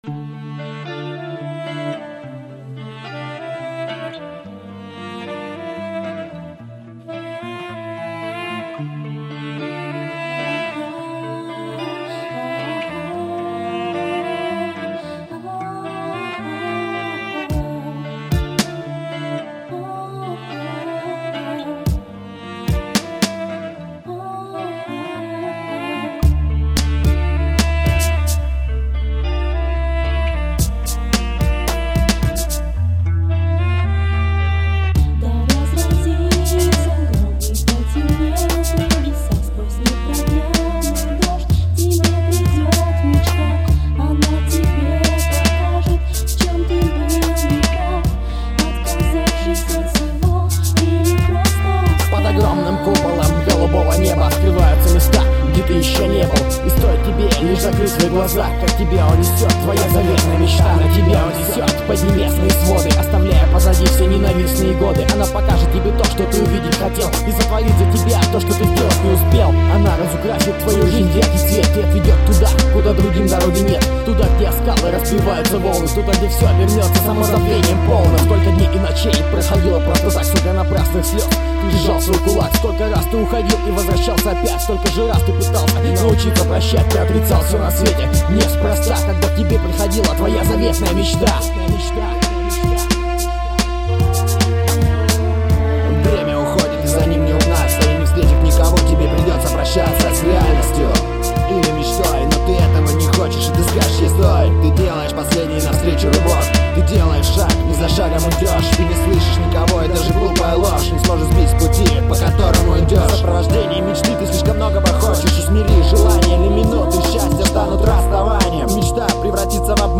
• Жанр: Хип-хоп